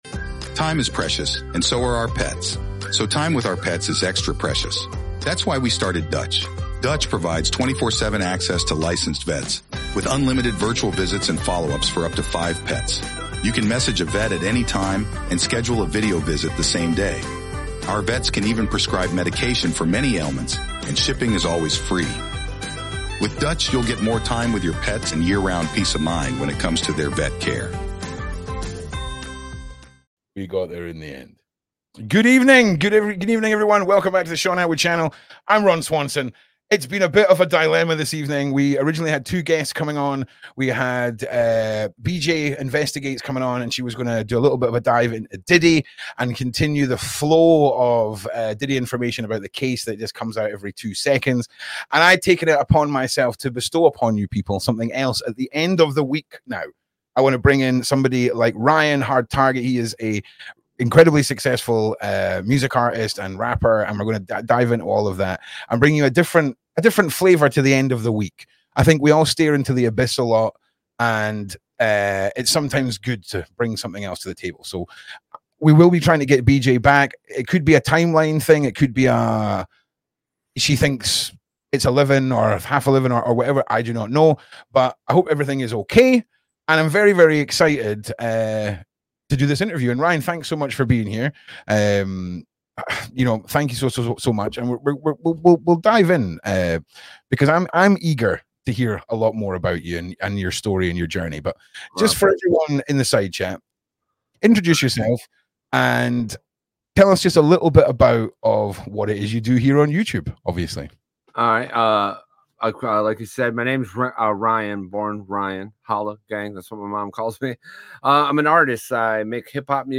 HARD TARGET INTERVIEW - HIP HOP ARTIST